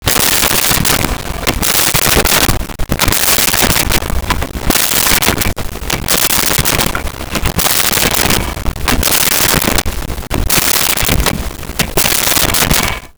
Clock Winding 2
clock-winding-2.wav